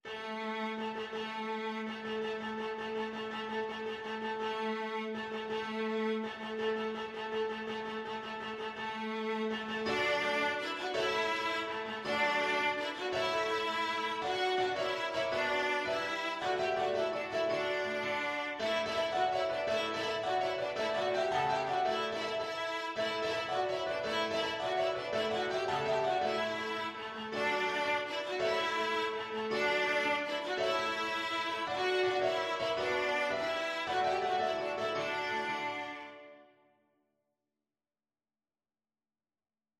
Viola version
With energy .=c.110
6/8 (View more 6/8 Music)
A4-A5
Classical (View more Classical Viola Music)